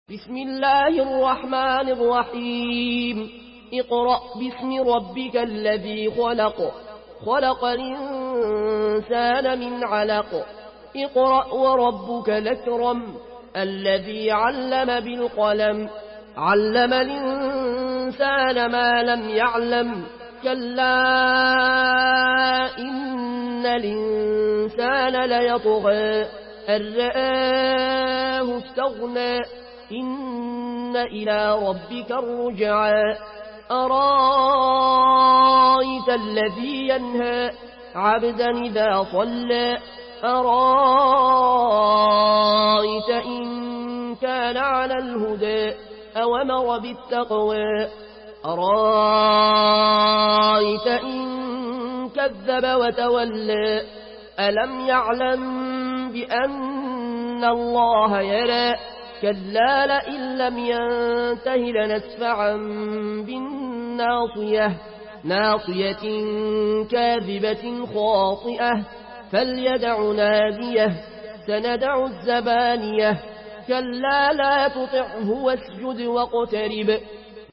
Une récitation touchante et belle des versets coraniques par la narration Warsh An Nafi From Al-Azraq way.
Murattal